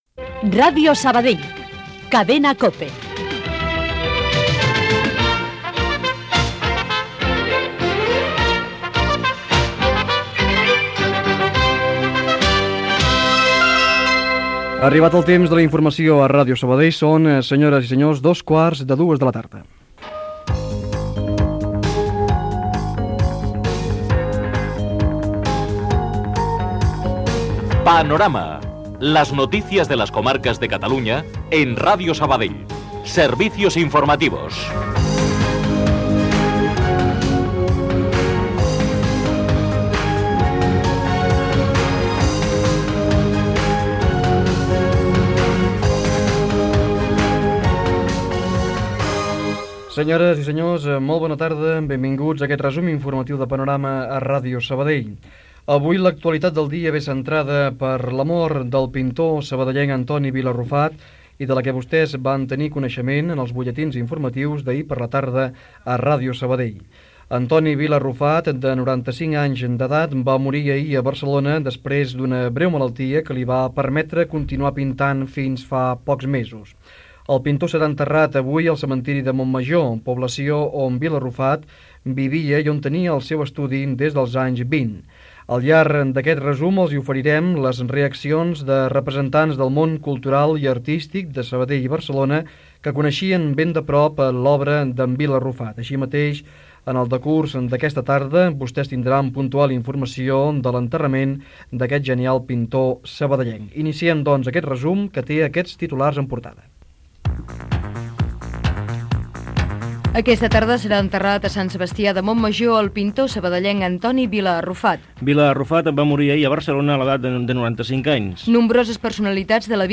Careta , inici del programa amb la notícia de la mort del pintor sabadellenc Antoni Vila Arrufat i titulars informatius.
Informatiu